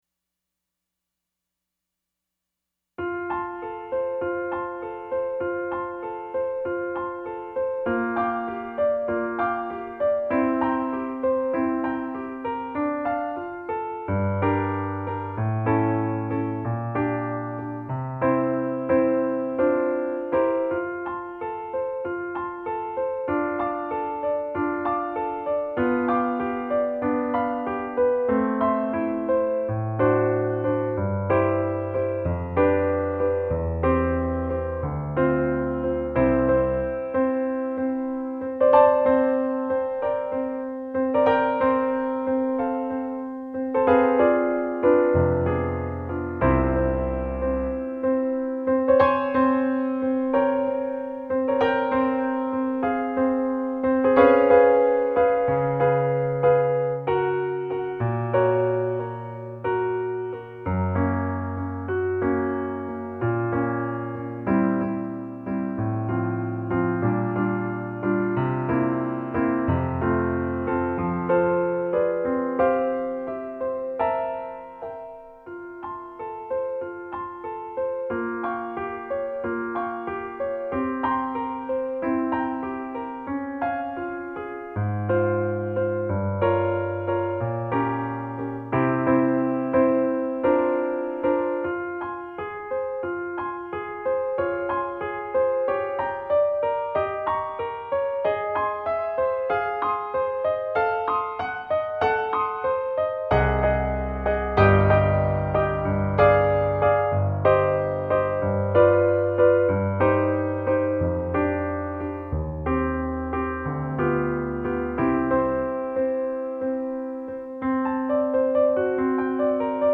Trumpet & Piano